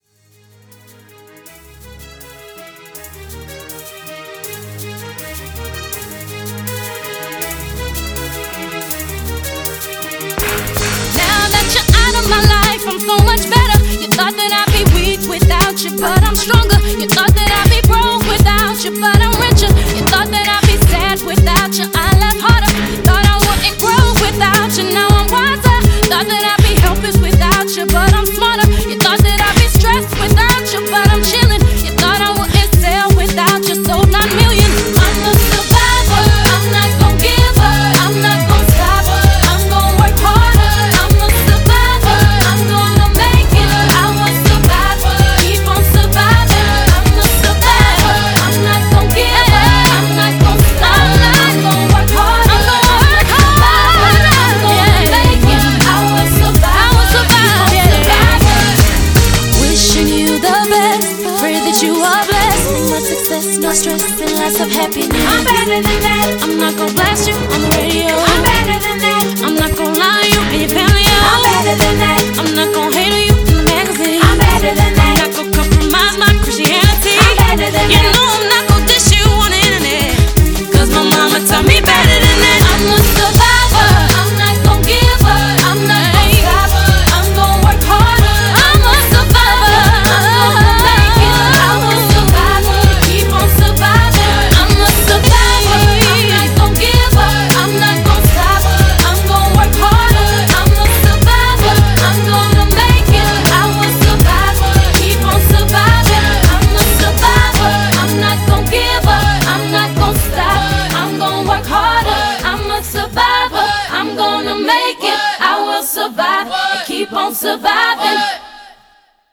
BPM161
MP3 QualityMusic Cut